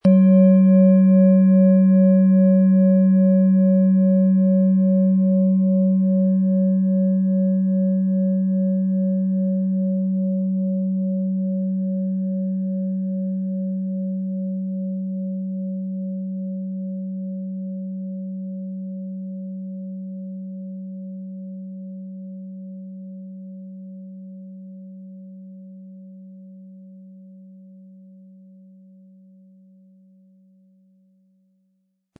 Von Hand hergestellte Klangschale mit dem Planetenton Biorhythmus Geist.
Um den Original-Klang genau dieser Schale zu hören, lassen Sie bitte den hinterlegten Sound abspielen.
PlanetentonBiorythmus Geist
MaterialBronze